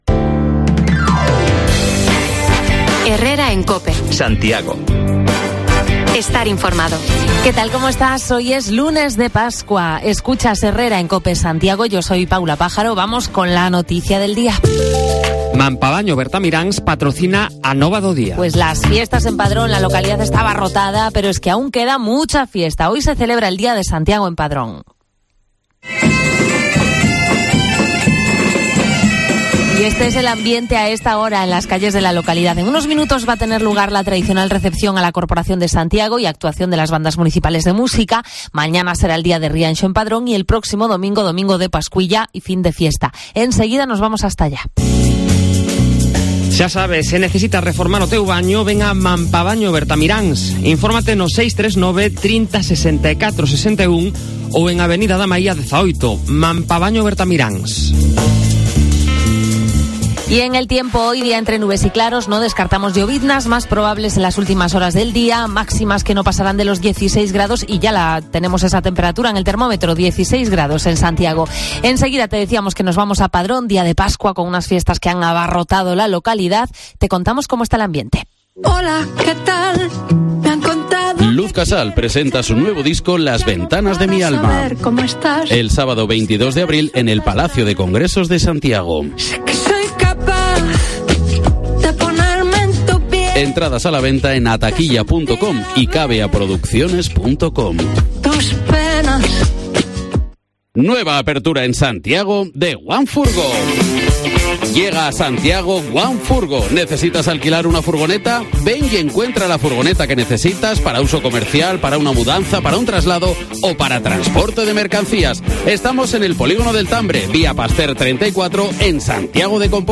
Lunes de Pascua, nos vamos en directo hasta Padrón que celebra sus días grandes de fiesta y hoy tradicional recepción a la corporación de Santiago.